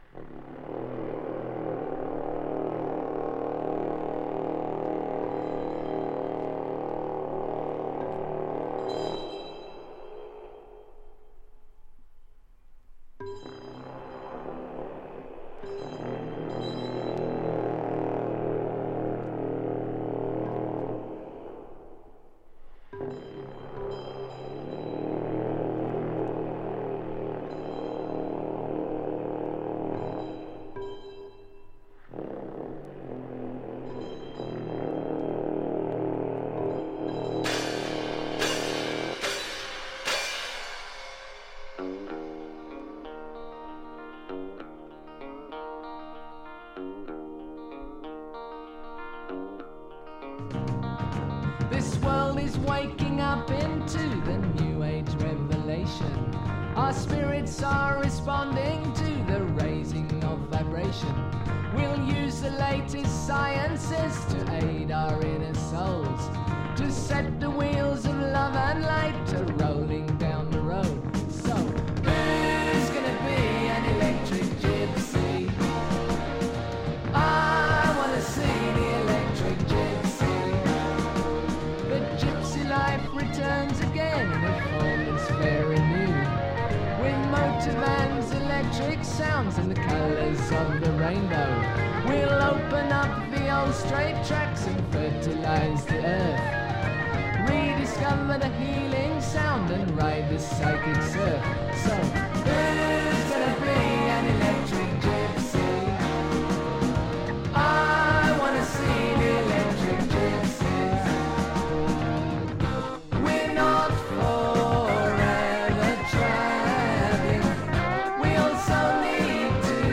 【PSYCHEDELIC】 【JAZZ ROCK】
スペイシー&サイケデリック・ロック！
ミニマル&トリッピーなギターソロから幕を開けるファンキーなスペイシー・ロック